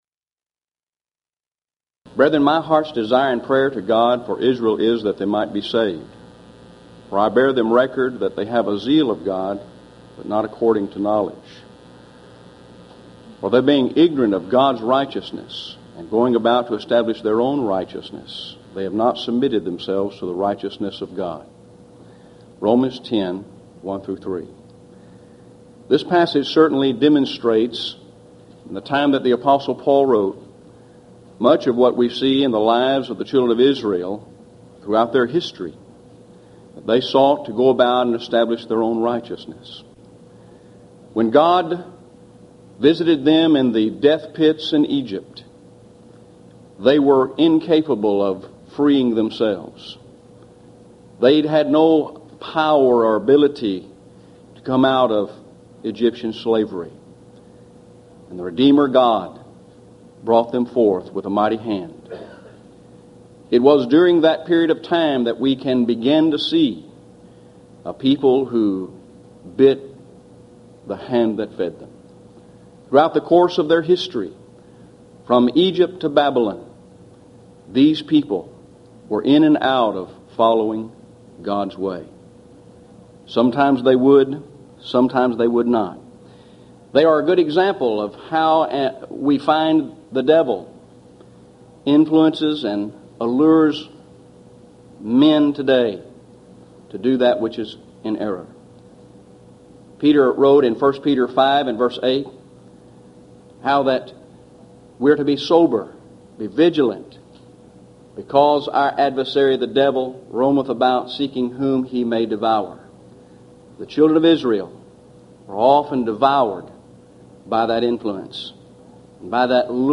Series: Houston College of the Bible Lectures